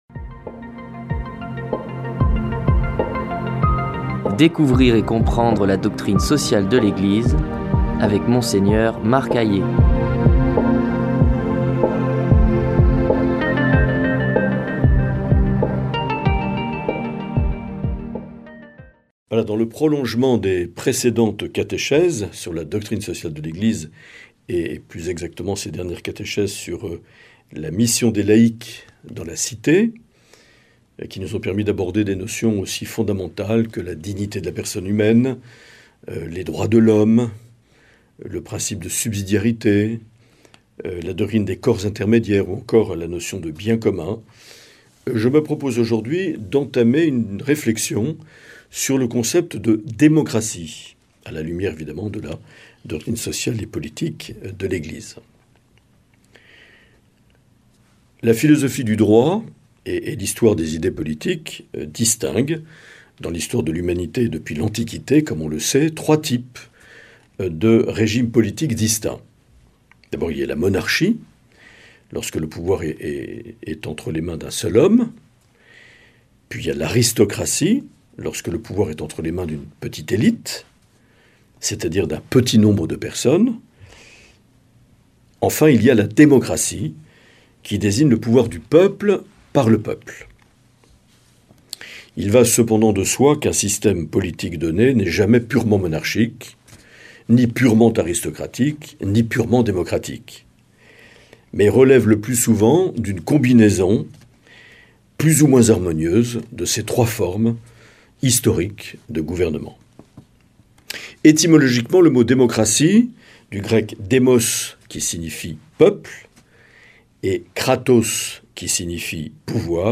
Monseigneur Marc Aillet
Présentateur(trice)